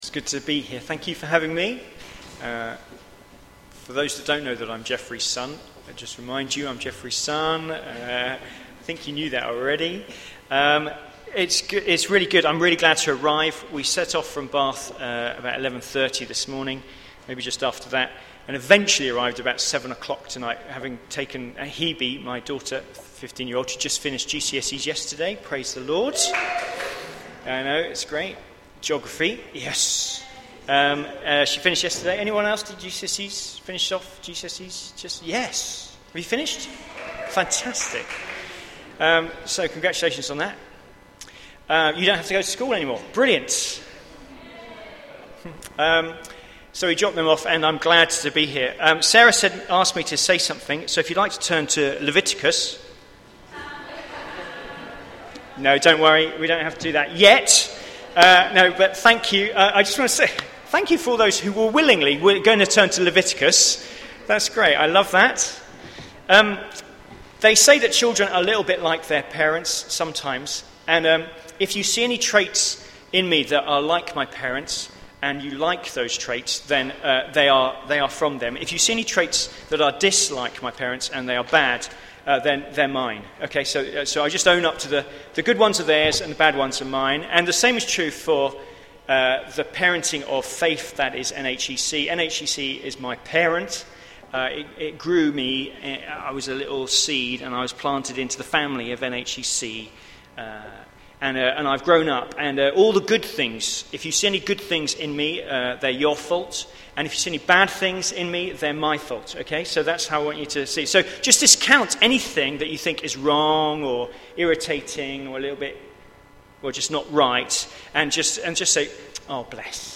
Intro Sermon